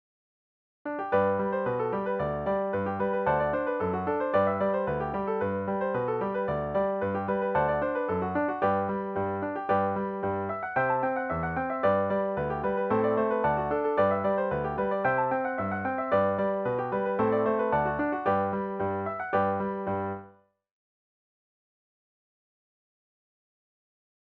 DIGITAL SHEET MUSIC - PIANO ACCORDION SOLO